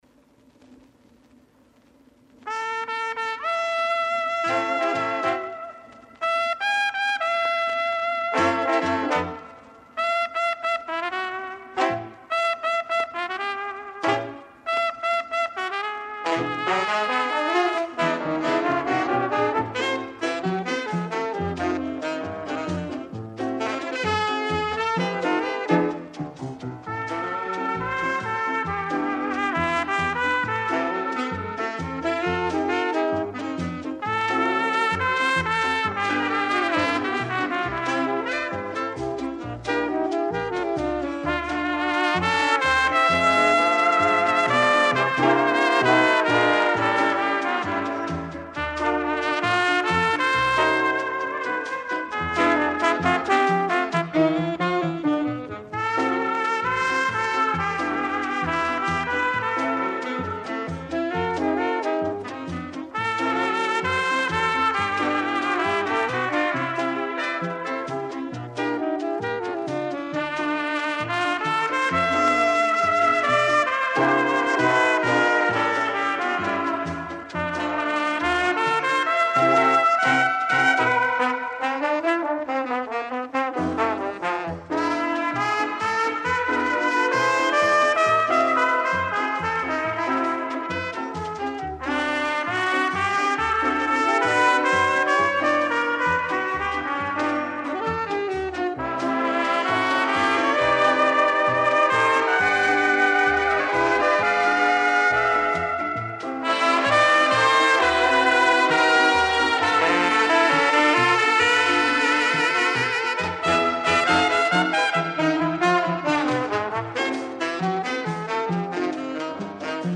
инстр. ансамбль